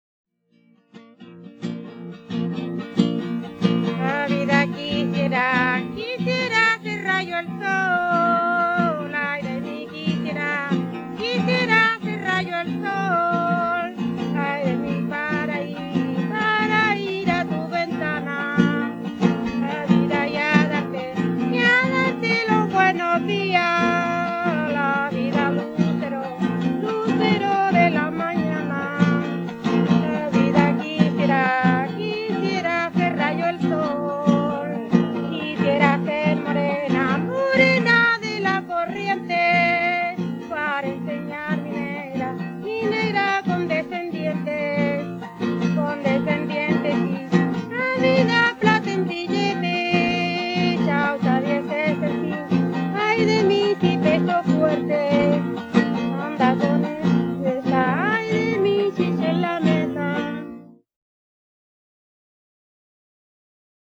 quien se acompaña con una guitarra afinada por trasporte.
Música tradicional
Folklore
Cueca